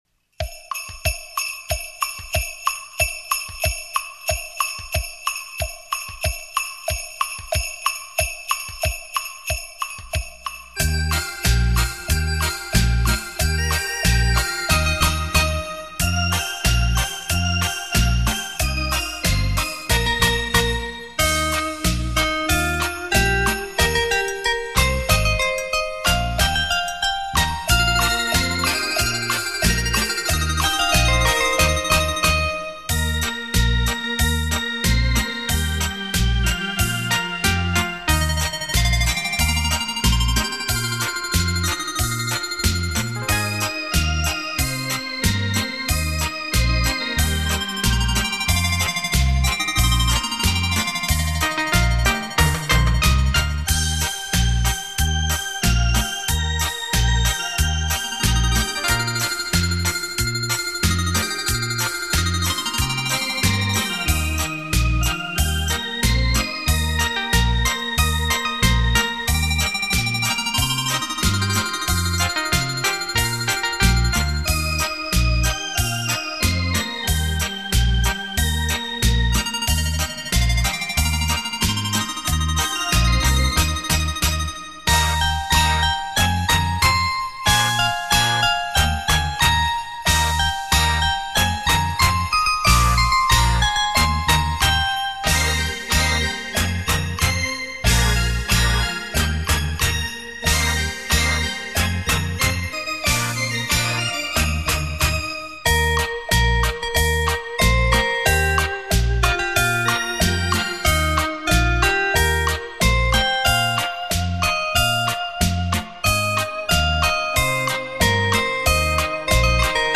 双立体